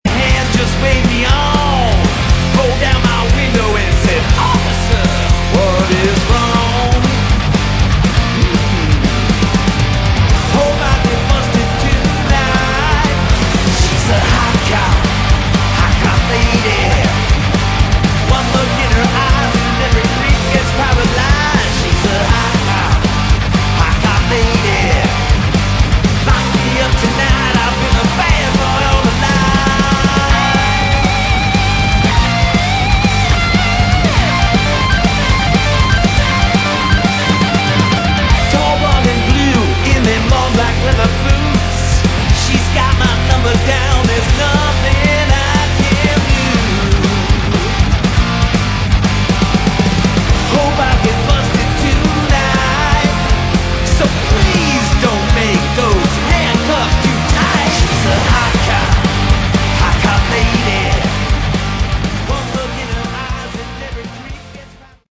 Category: Hard Rock
lead and backing vocals
guitar, keys
bass, backing vocals